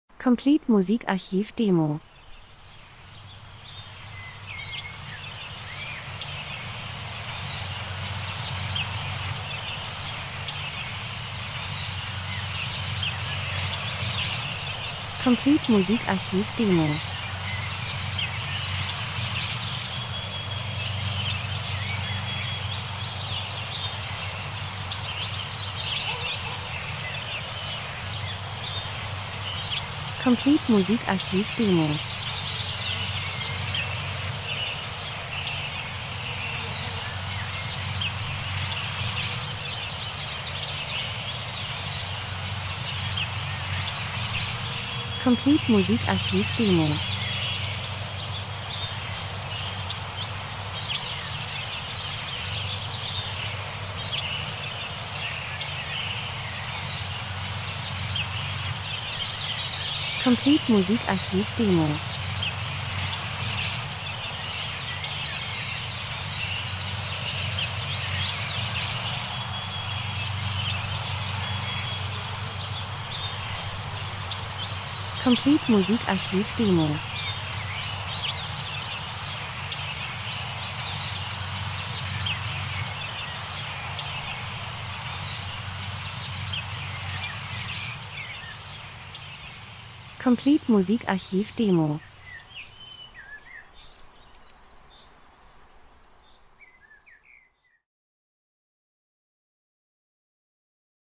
Frühling -Geräusche Soundeffekt Natur Vögel Wind Verkehr 01:40